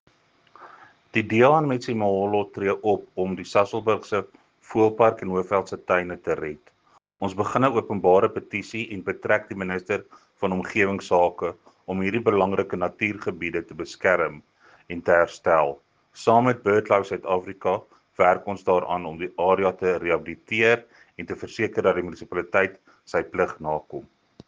Afrikaans soundbites by Cllr Jacques Barnard and